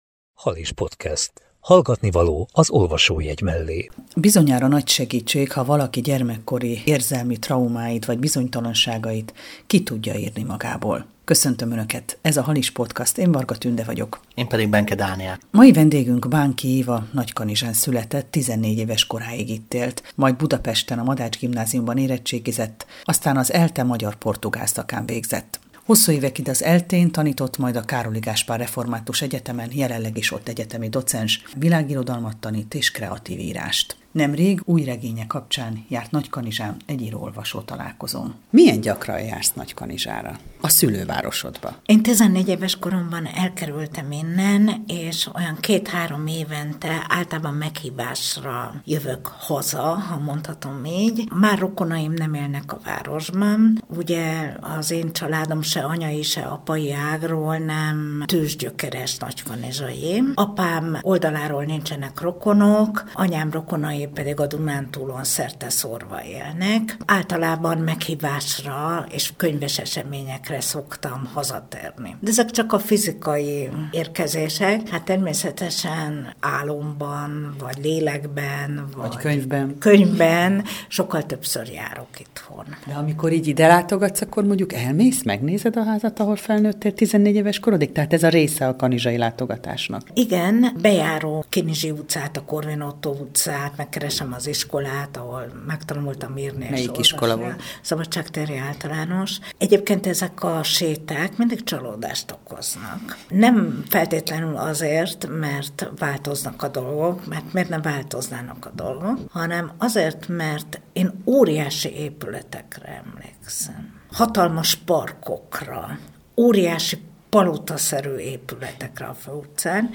Halis Podcast 73 - Apjalánya vagyok, noha nem lehetek - Beszélgetés